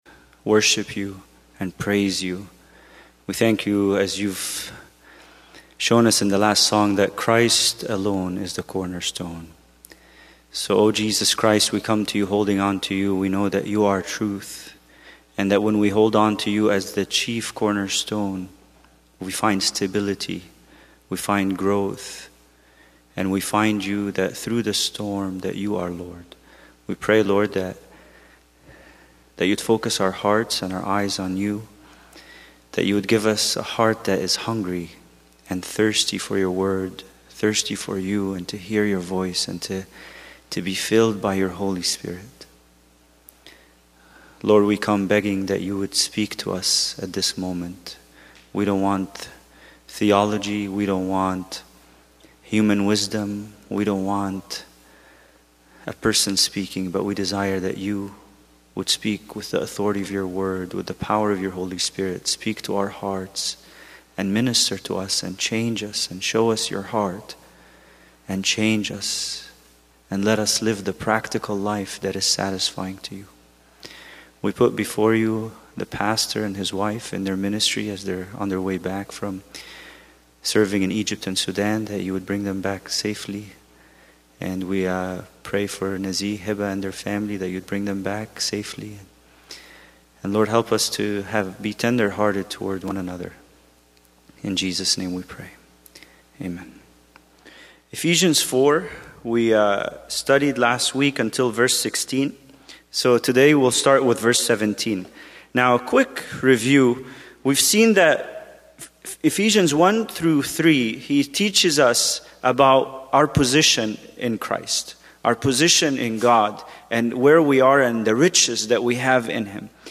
Bible Study: Ephesians 4:17-19